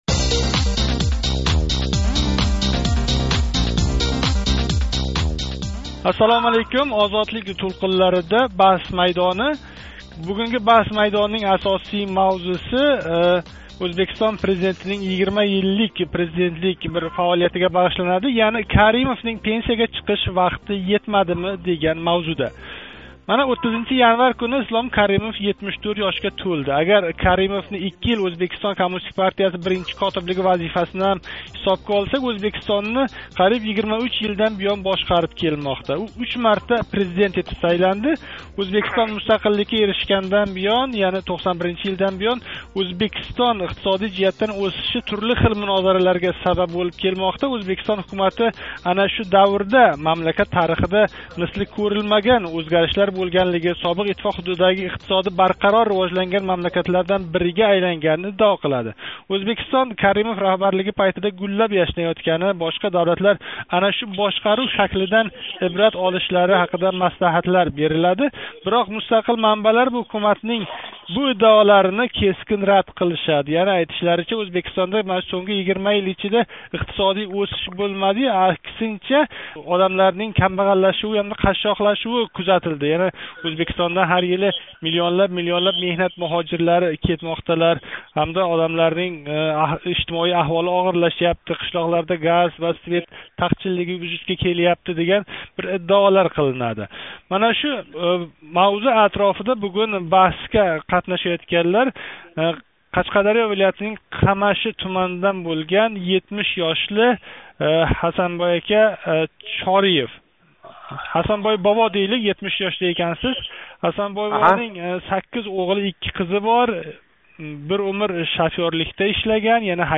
"Баҳс майдони" эшиттиришида қашқадарёлик ва жиззахлик тингловчи Ўзбекистон Президенти Ислом Каримовнинг мамлакатни бошқариш қудрати бор-йўқлиги ҳақида тортишади